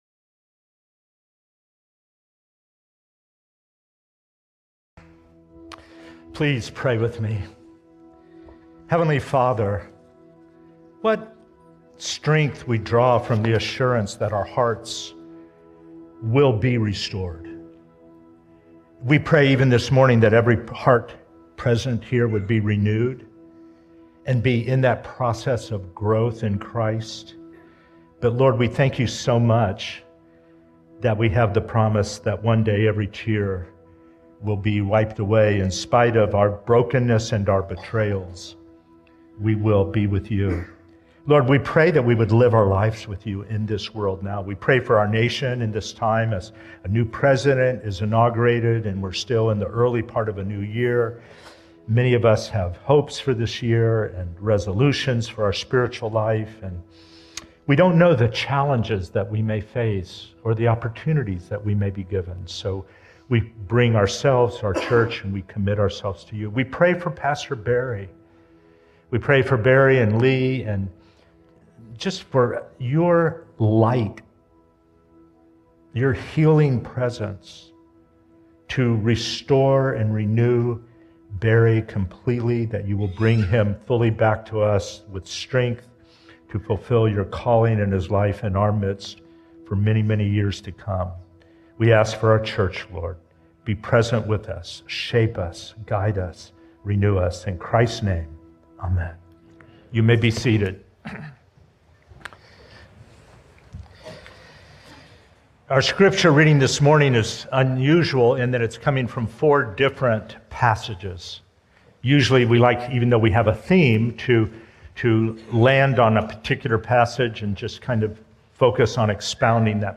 1 The Myths of Generosity Sermon 49:17